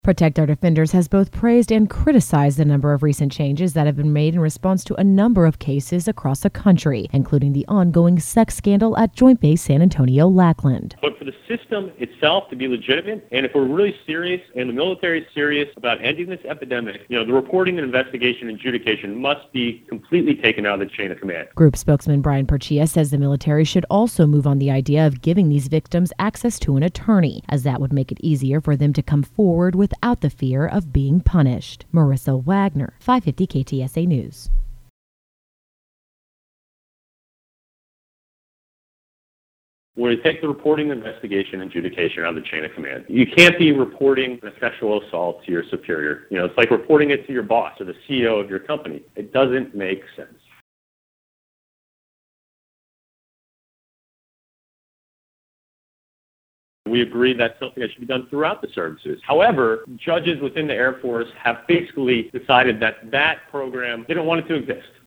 550 KTSA News in San Antonio reports on Protect Our Defenders: